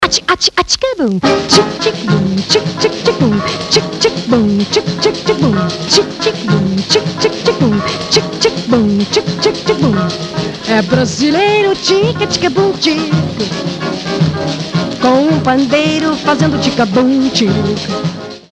• Качество: 320, Stereo
поп
Latin Pop
ретро
саундтрек